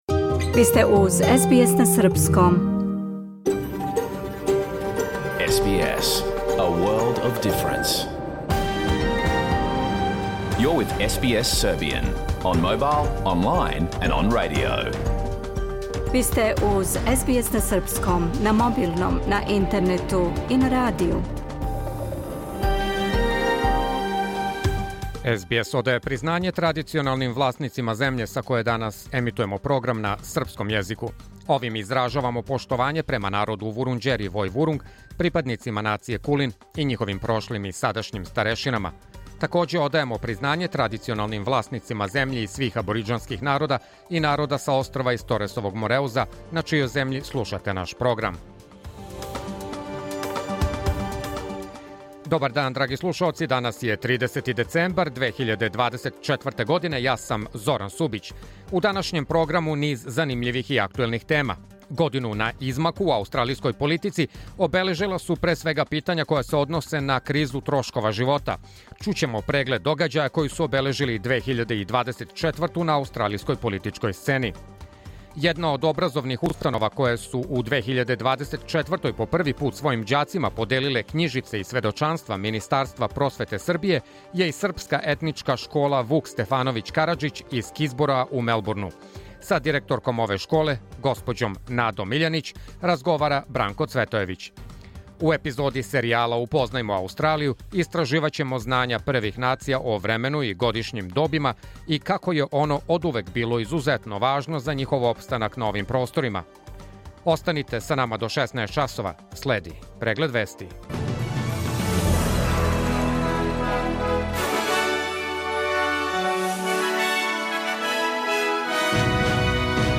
Програм емитован уживо 30. децембра 2024. године